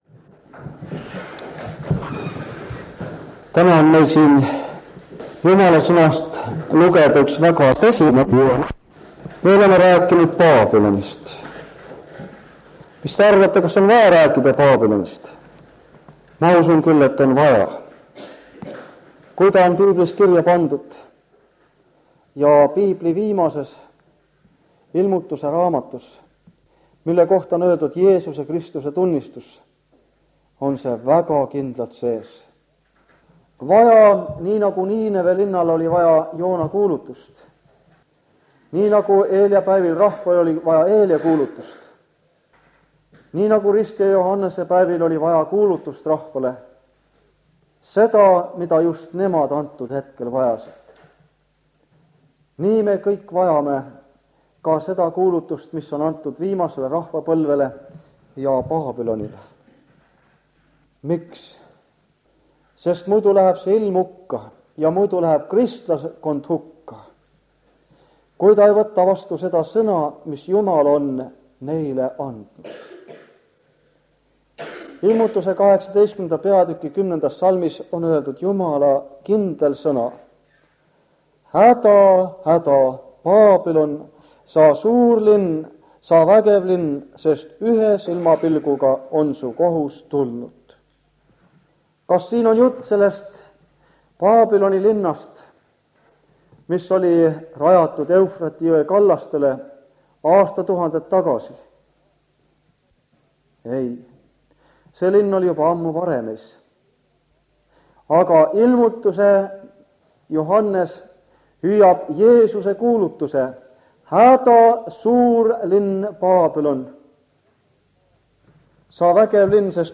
Jutlused
Jutluste minisari Kingissepa adventkoguduses 1978 aastal.